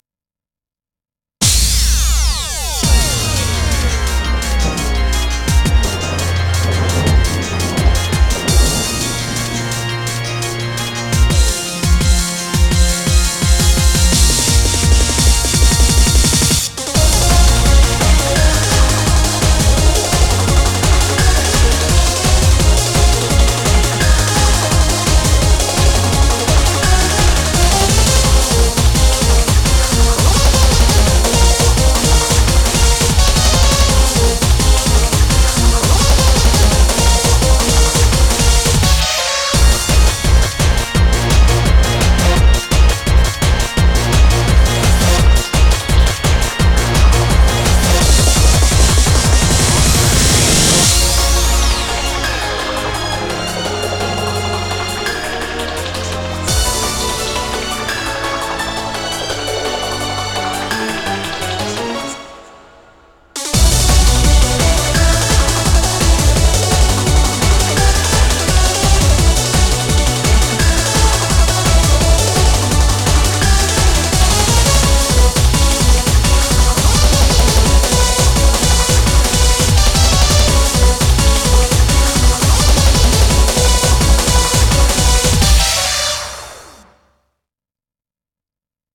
BPM170
layered this song with a lot of percussion and harmony